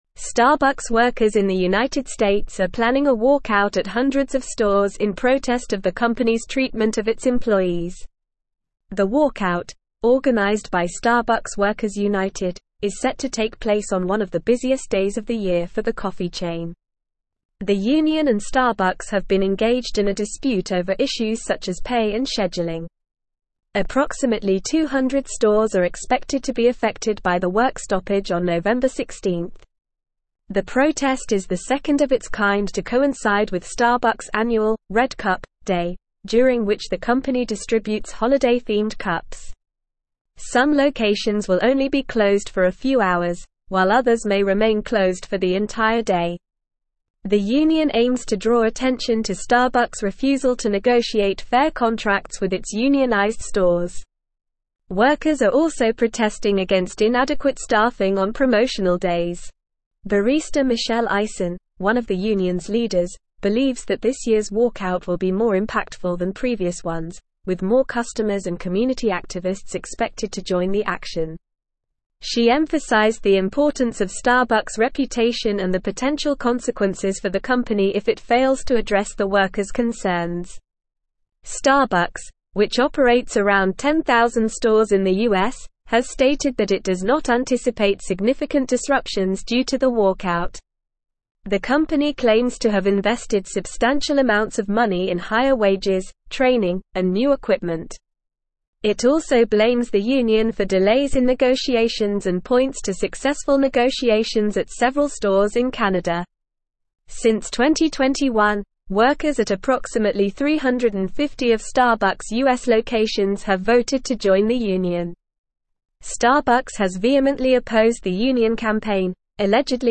Normal
English-Newsroom-Advanced-NORMAL-Reading-Starbucks-workers-plan-walkout-over-pay-and-conditions.mp3